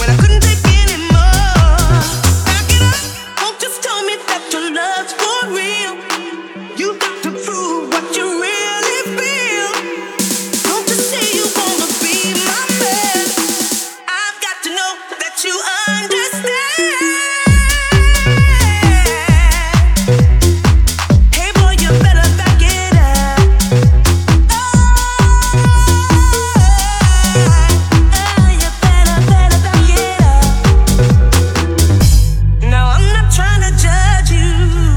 Жанр: Танцевальные / Хаус
Dance, House